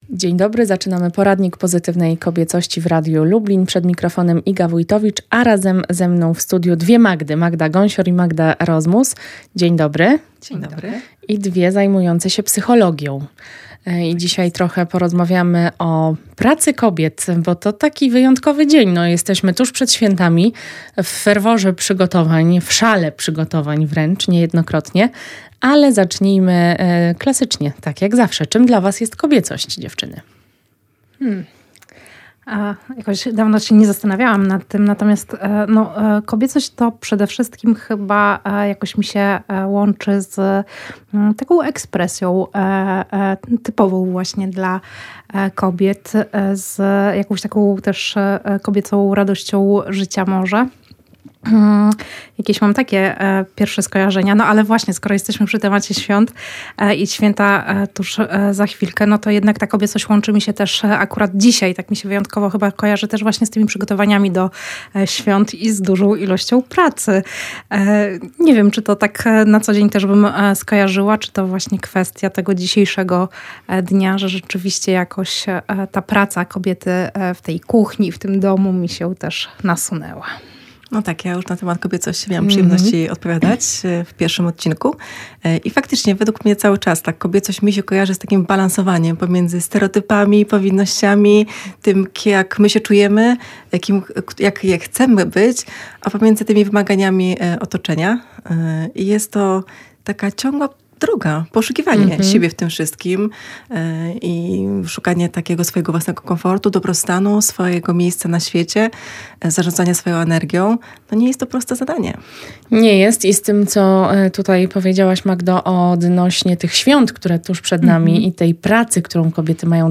Dziś przedświąteczna rozmowa o tym jak nie dać się zwariować presji przygotowań do świąt.